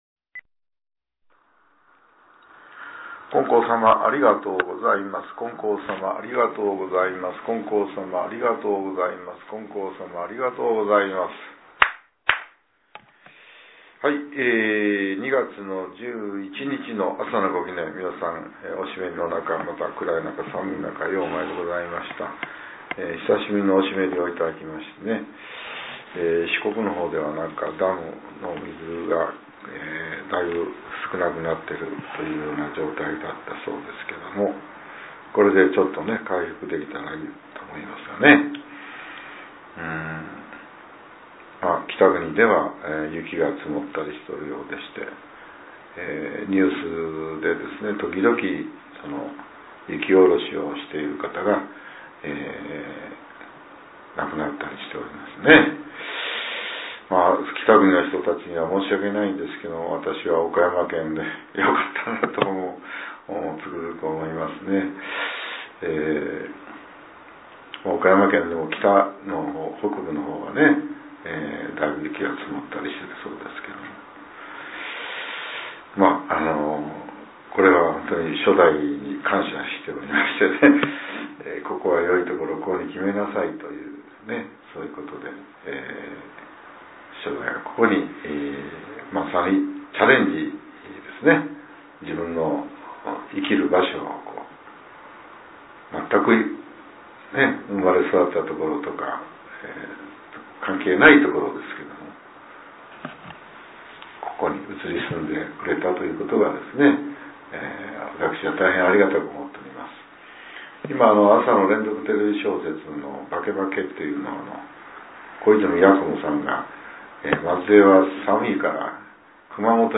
令和８年２月１１日（朝）のお話が、音声ブログとして更新させれています。 きょうは、前教会長による「今月今日でチャレンジ」です。